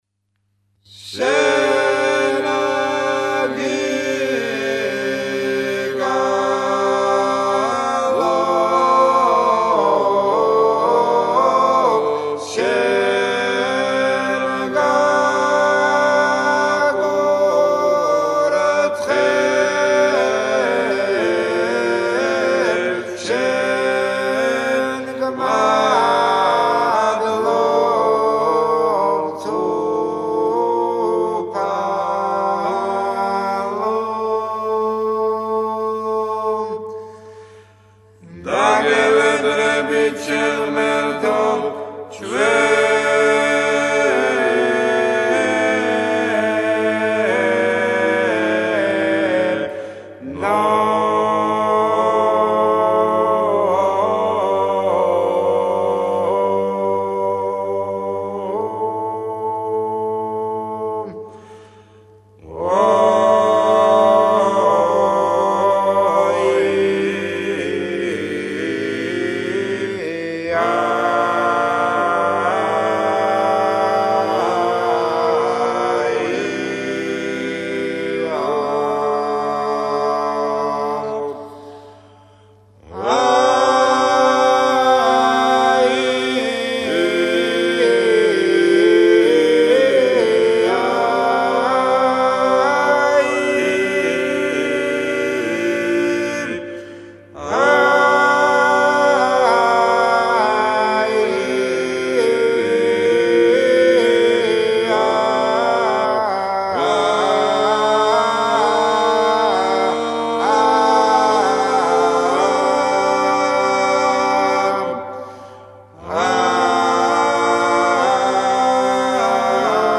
საგალობელი